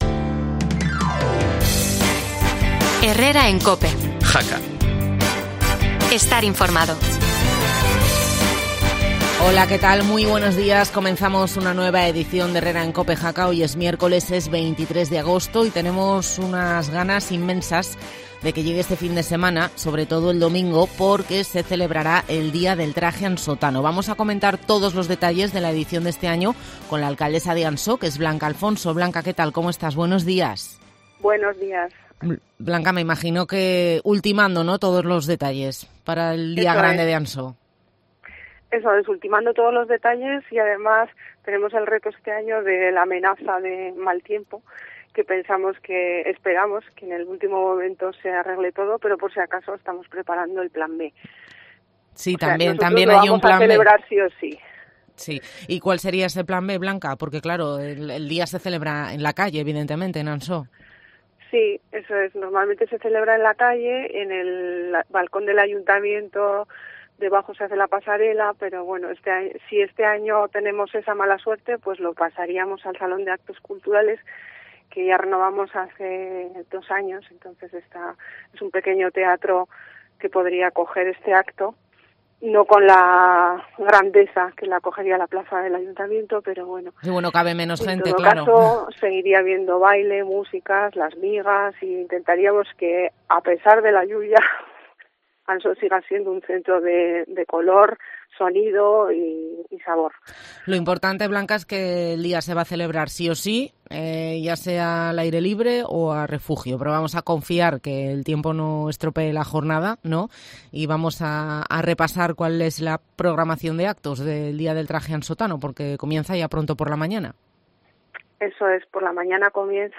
Blanca Alfonso, alcaldesa de Ansó